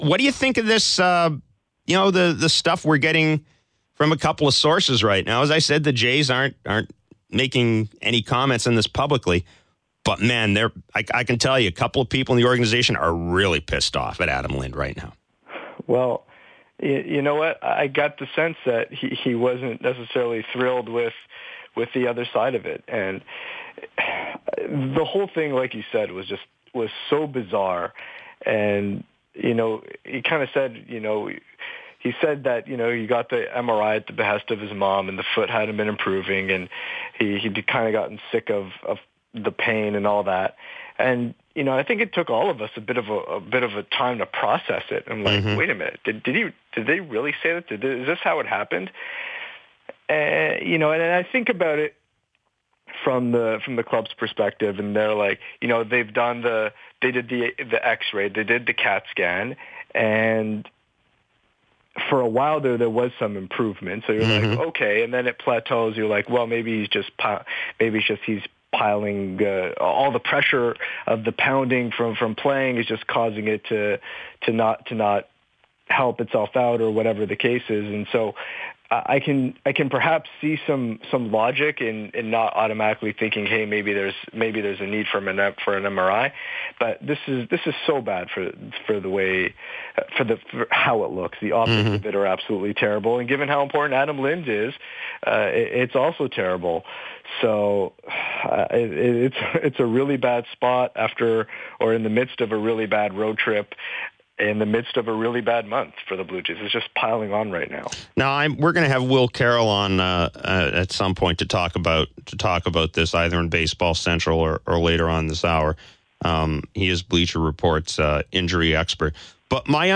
During his show Thursday on Sportsnet 590 The Fan